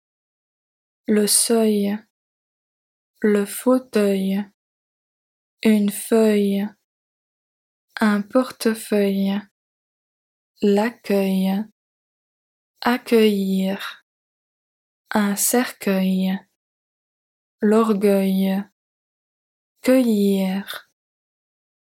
Lesson 31 - Listening practice - 60